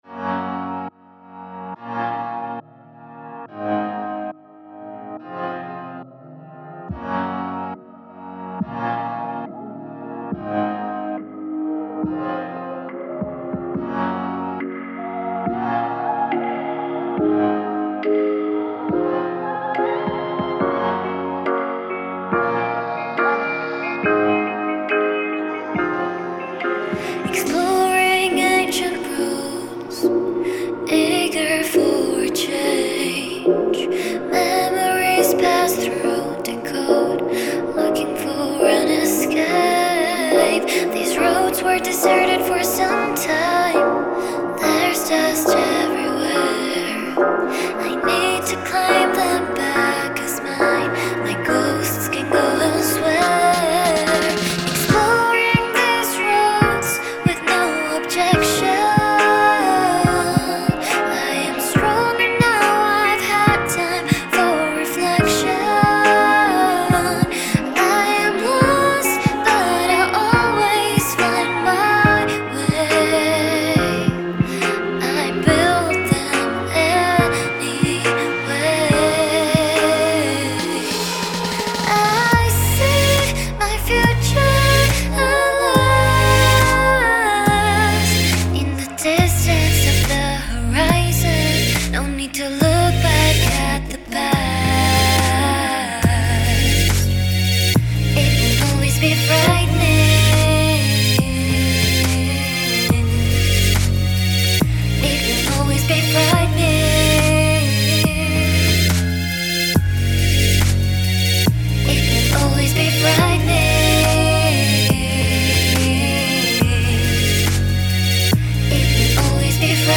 I started watching a couple of hour-long tutorials on mixing just to learn how to mix properly because the song was sounding muddy.
I did a lowcut on the piano as the low end wasn't necessary. then cut out some frequencies with an annoying sound that I didn't want in there.